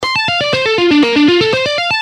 This hybrid picking style combines the best of alternate picking and sweep picking, enabling you to play scales, arpeggios, and complex runs with smoothness and precision.
economy-picking-lesson.gpx-4.mp3